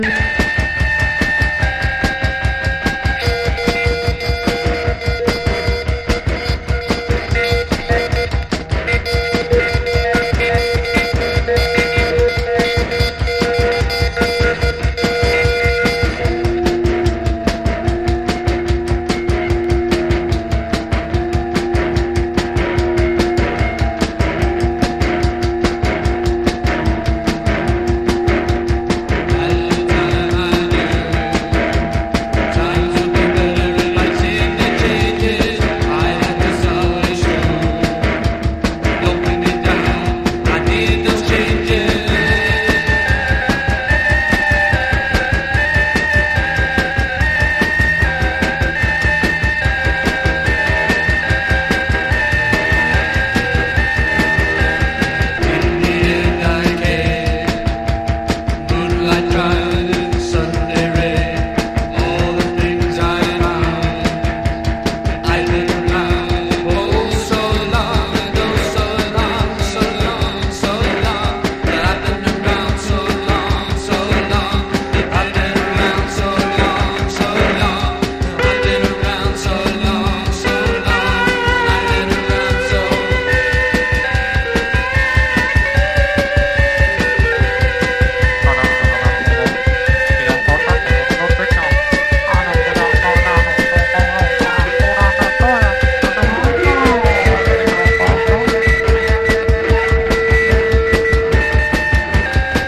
英国カルト・ポストパンク/エクスペリメンタル・バンド唯一のフル・アルバム！